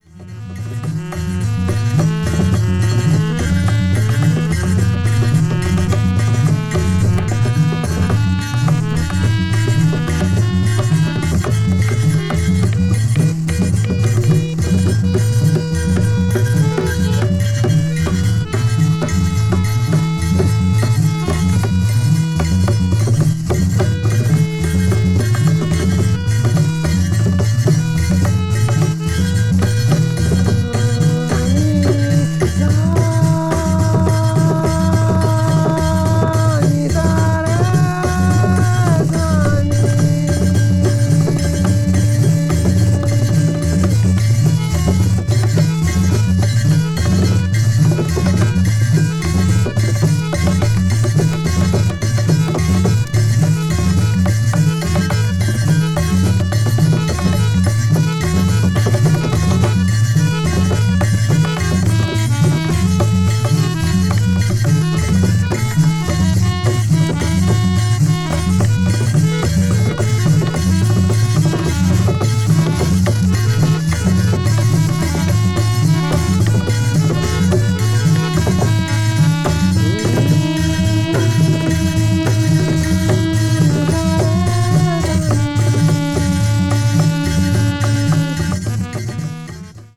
avant-jazz   ethnic jazz   experimental jazz   free jazz